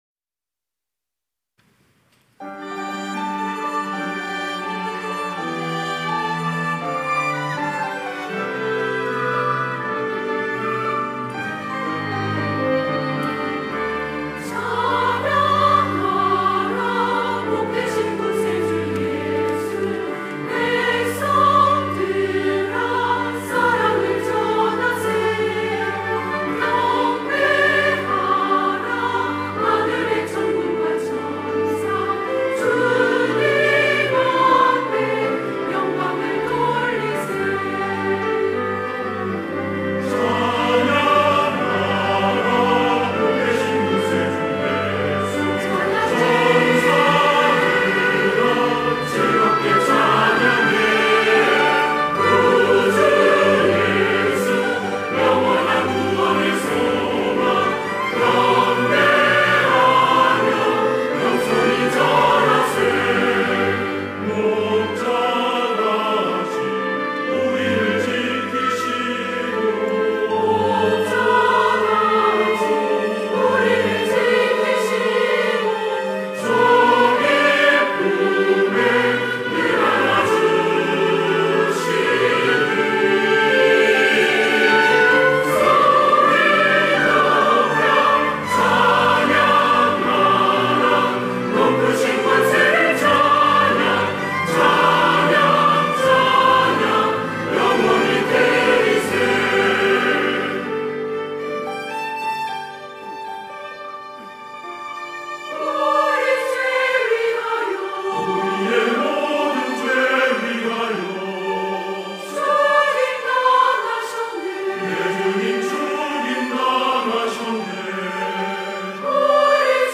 할렐루야(주일2부) - 찬양하라
찬양대